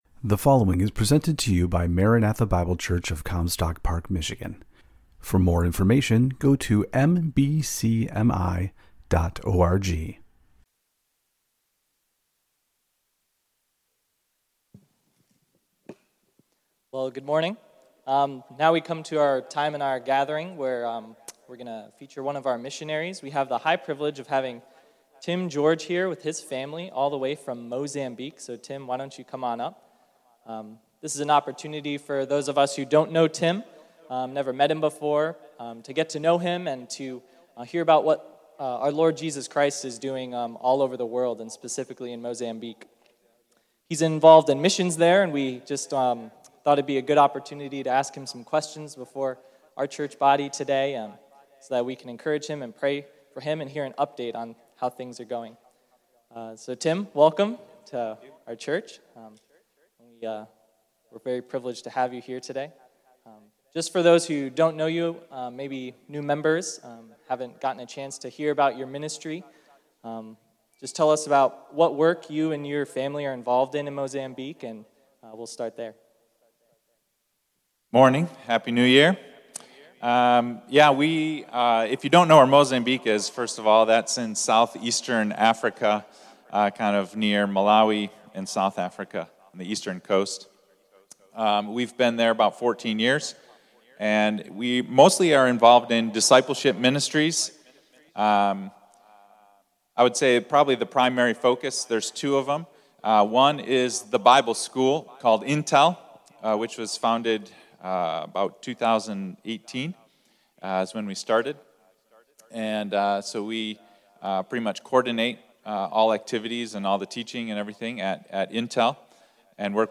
Elders’ Q & A